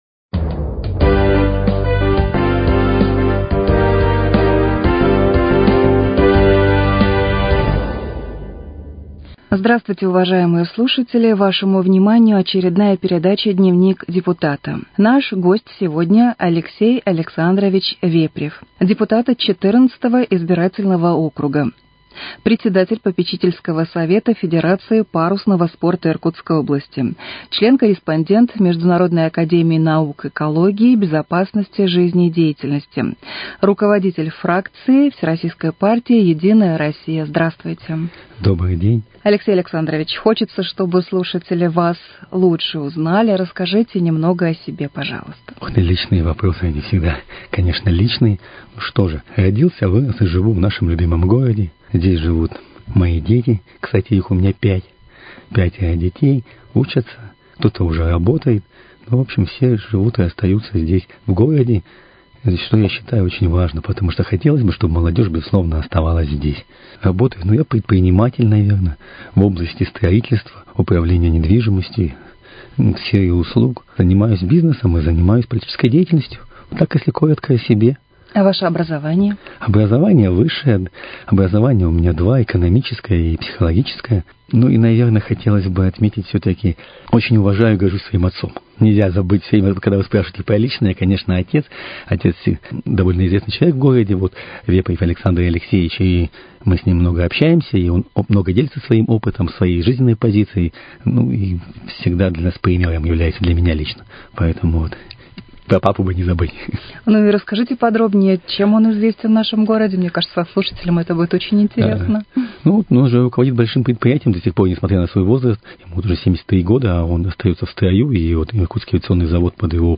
Участник беседы Алексей Вепрев, депутат Думы г.Иркутска от избирательного округа № 14.